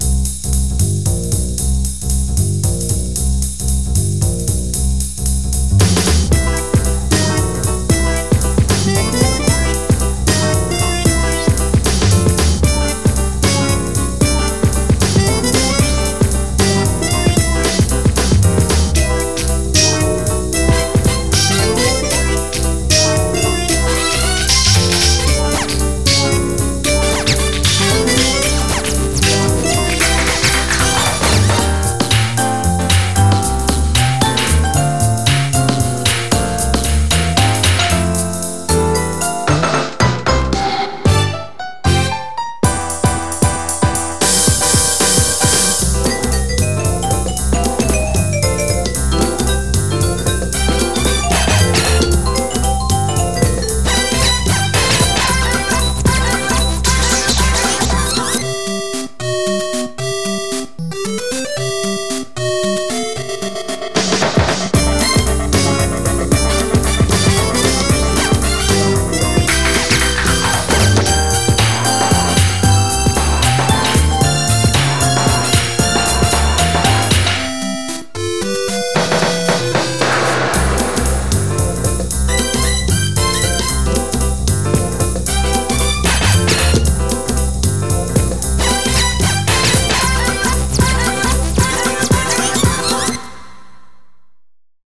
BPM114
Audio QualityPerfect (High Quality)
Better quality audio.